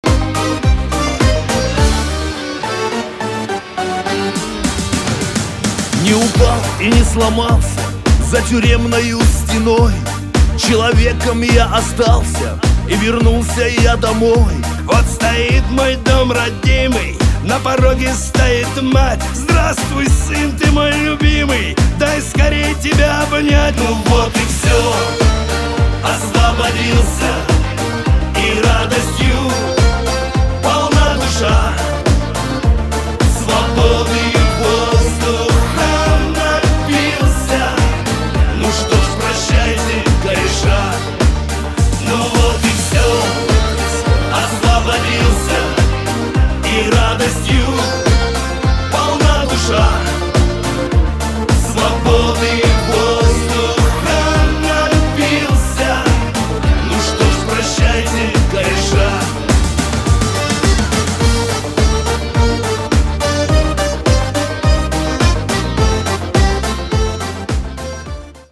Шансон рингтоны